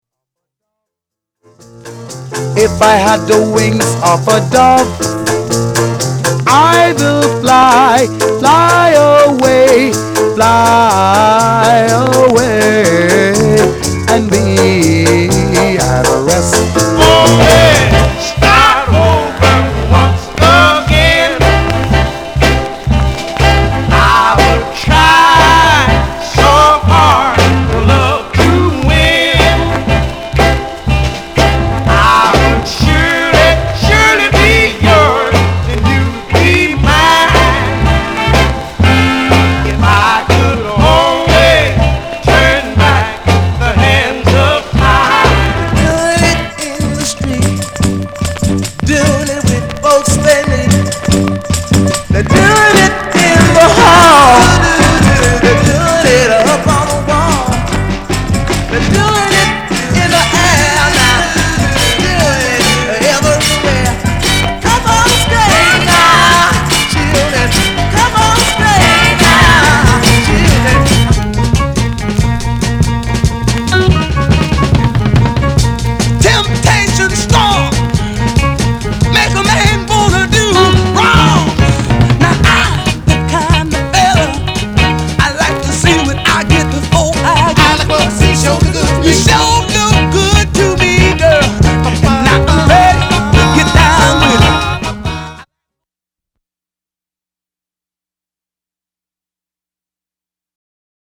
R&B、ソウル
has an uncredited harmony voice in a rueful, reflective song
A great-sounding record well worth acquiring.